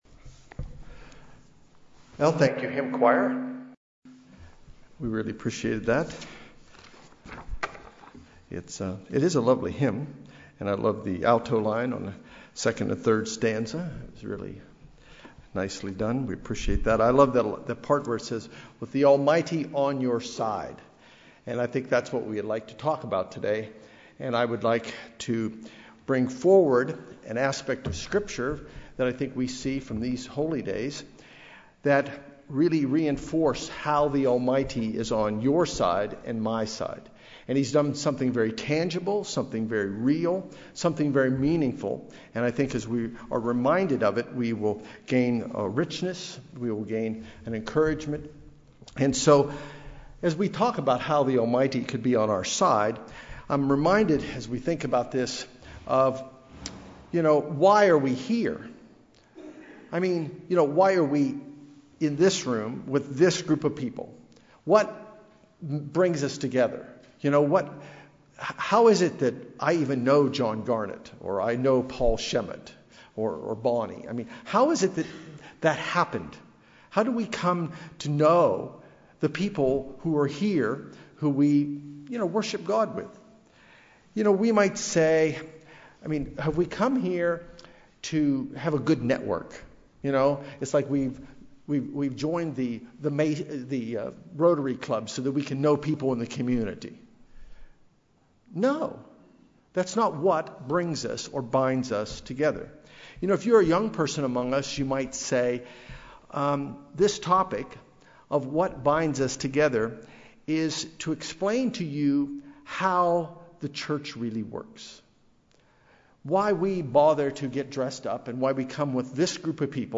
Sermons
Given in Los Angeles, CA